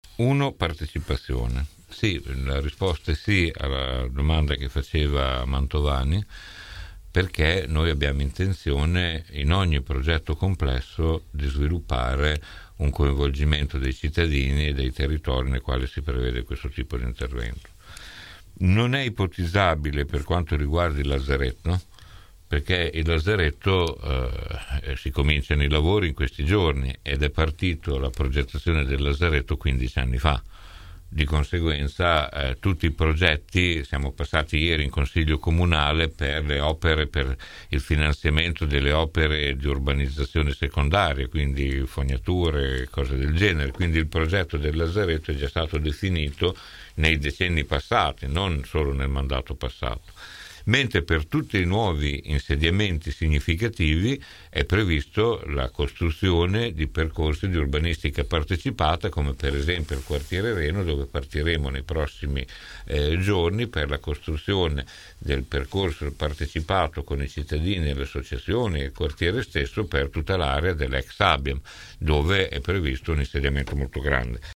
17 nov. – Questa mattina ai nostri microfoni l’assessore all’urbanistica, ambiente e sport del comune di Bologna Maurizio Degli Esposti ha risposto su una serie di punti a cominciare dalla questione dei “23 saggi” chiamati a “riprogettare Bologna” che, afferma l’assessore, non hanno alcun potere decisionale